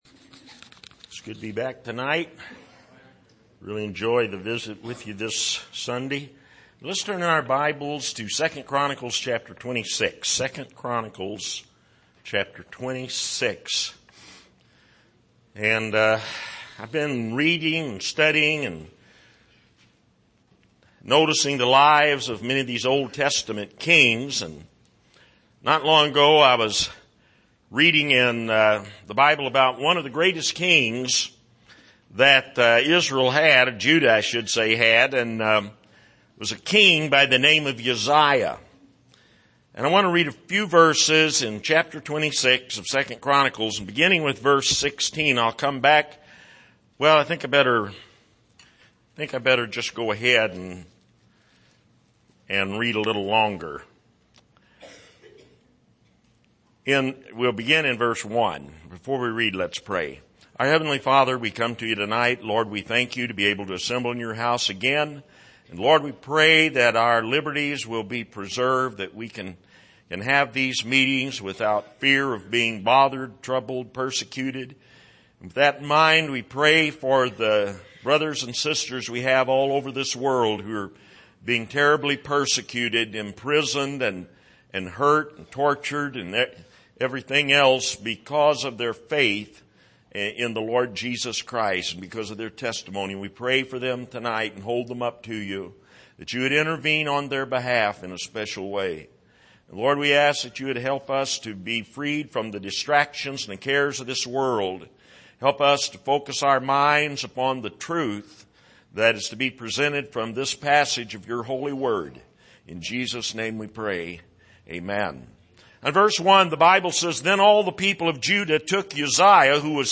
Passage: 2 Chronicles 26:1-21 Service: Sunday Evening